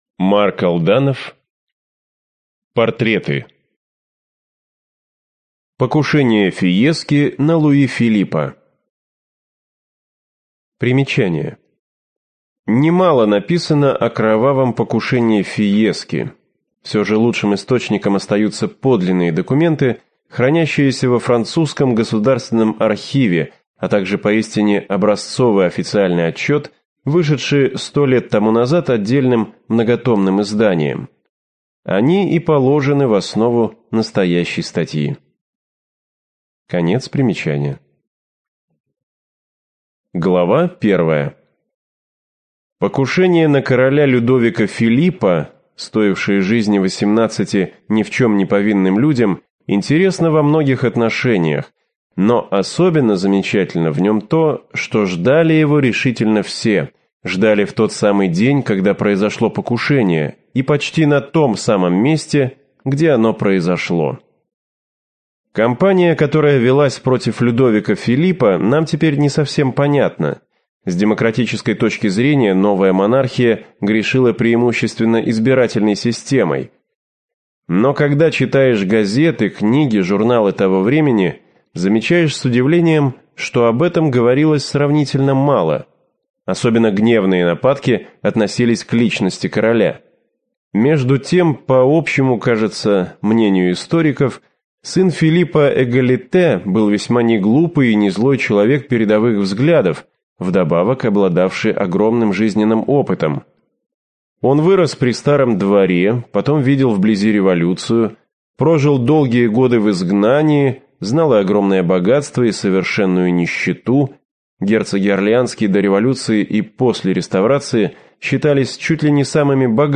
Аудиокнига Знаменитые покушения | Библиотека аудиокниг